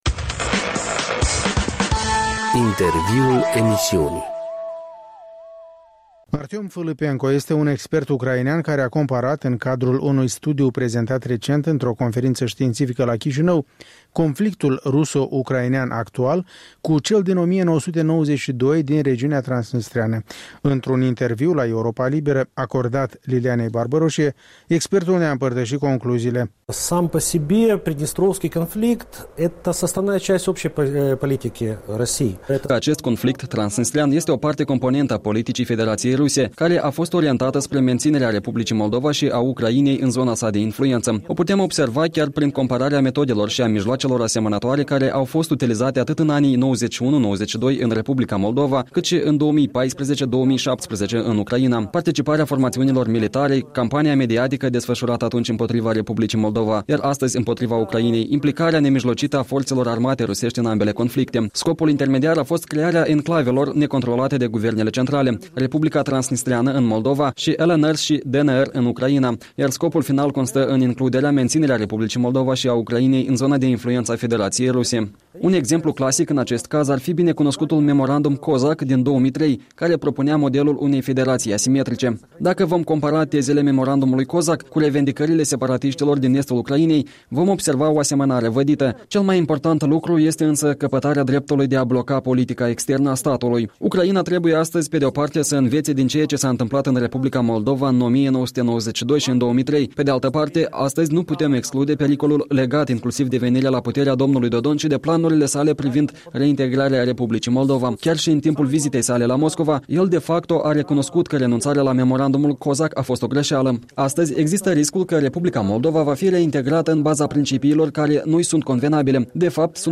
Un interviu cu un expert politic ucrainean din Odesa.